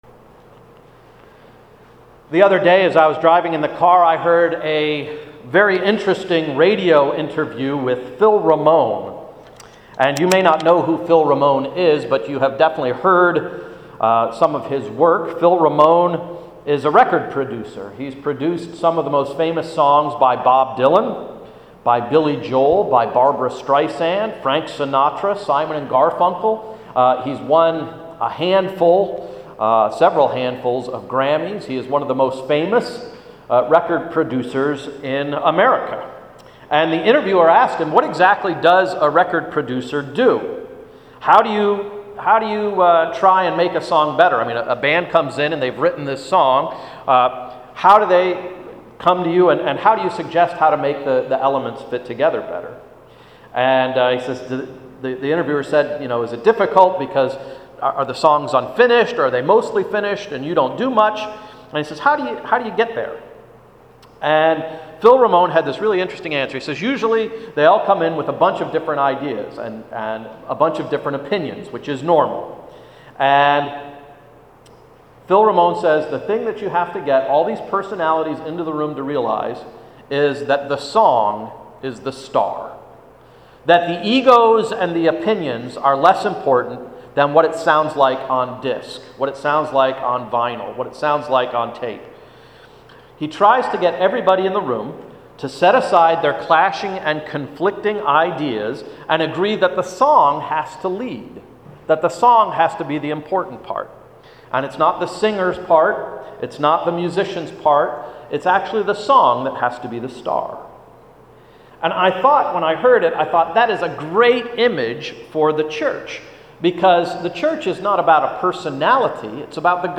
“No Harm, No Foul” –Sermon of September 4, 2011